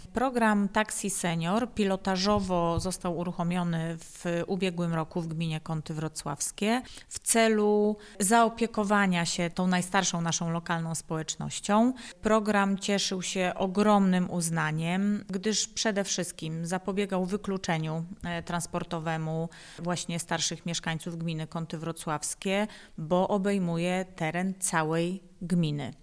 – Program „Taxi Senior”powstał z myślą o przeciwdziałaniu wykluczeniu transportowemu najstarszych mieszkańców – mówi Katarzyna Sebzda – Sztul, burmistrz gminy Kąty Wrocławskie.